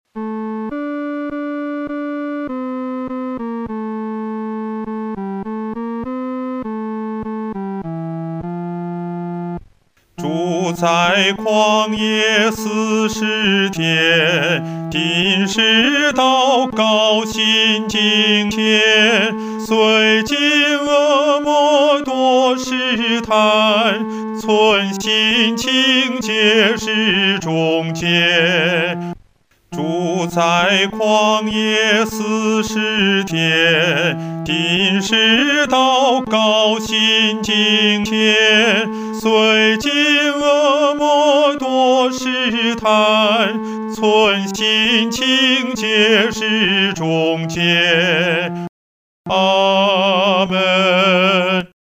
合唱
男高
本首圣诗由网上圣诗班 (石家庄一组）录制
这首诗歌宜用不太慢的中速弹唱。